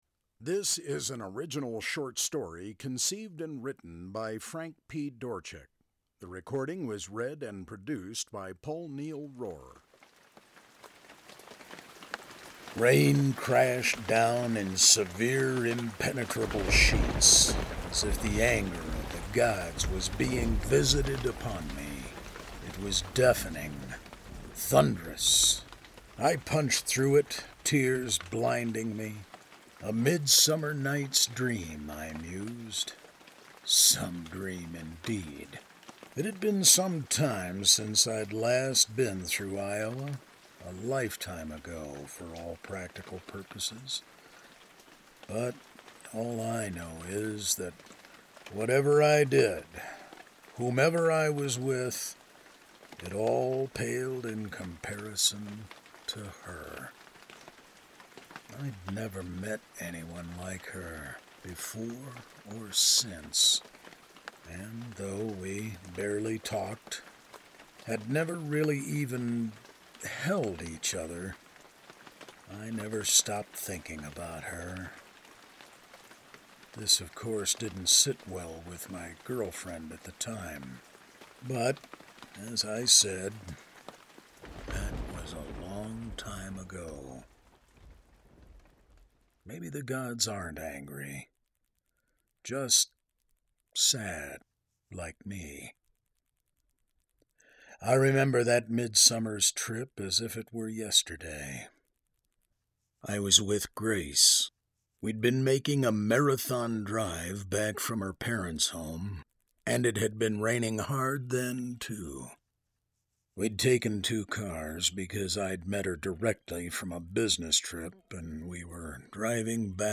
To see a story on paper is one thing…to hear yourself read it aloud…but to hear it performed is quite another!
Blondies-Short-Story.mp3